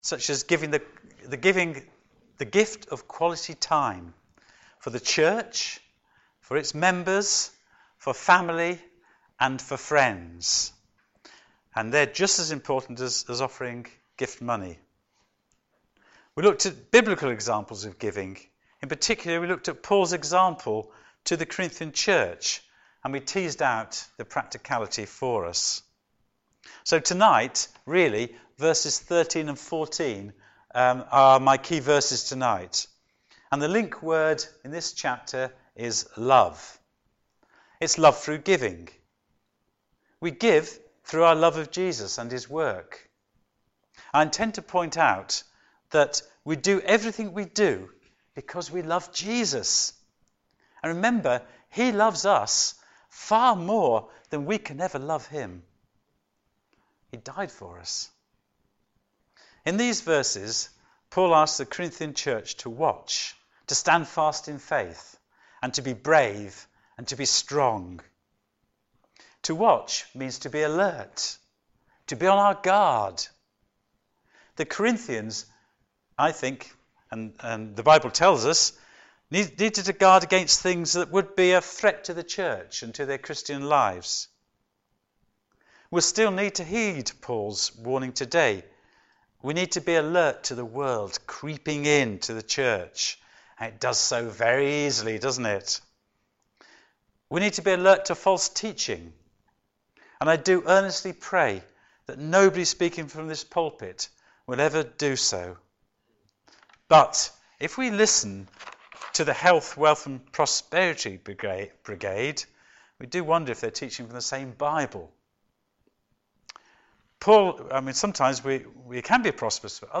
Working Together Practically 2 Sermon